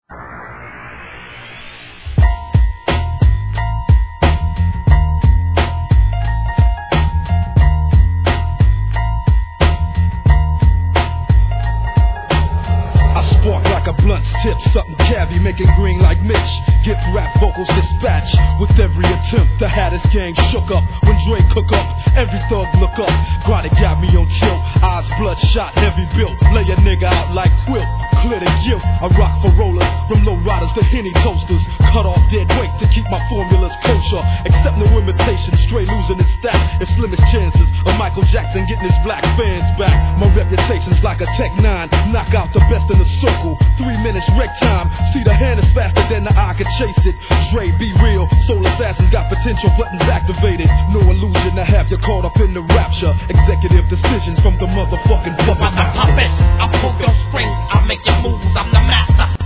G-RAP/WEST COAST/SOUTH
緊迫感溢れるピアノのLOOP!!